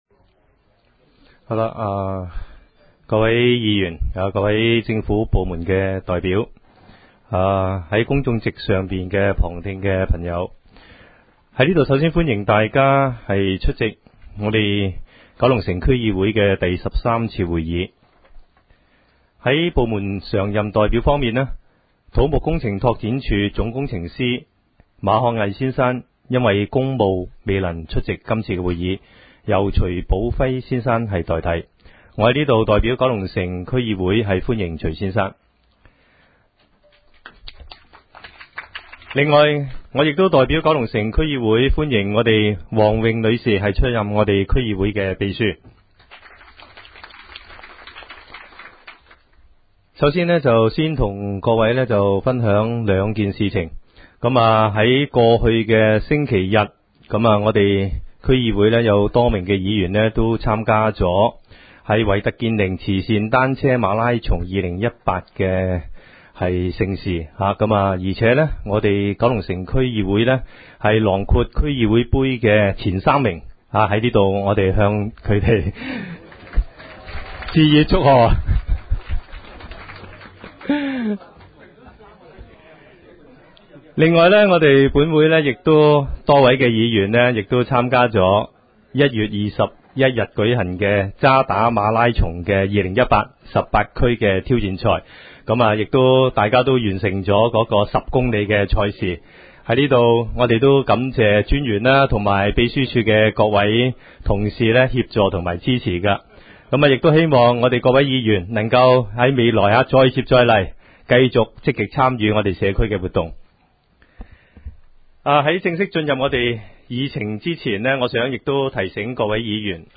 区议会大会的录音记录
九龙城民政事务处会议室